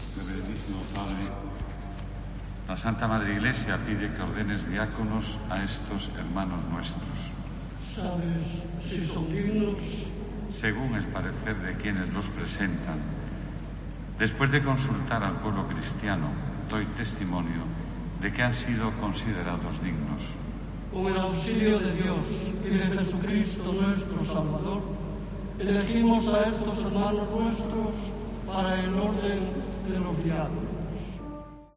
Monseñor Julián Barrio fue el encargado de presidir la ceremonia, que tuvo lugar en la Catedral de Santiago durante la tarde del viernes
Ordenación de los tres seminaristas como diáconos en la Catedral de Santiago de Compostela